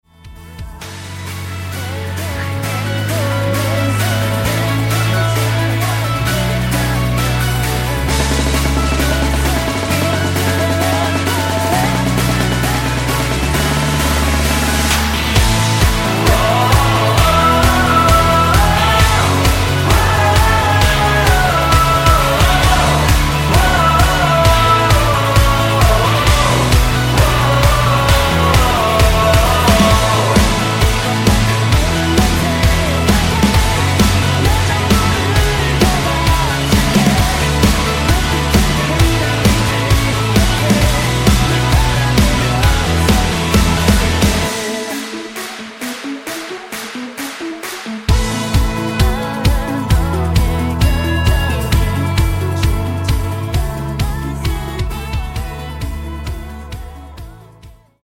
음정 원키 3:37
장르 가요 구분 Voice MR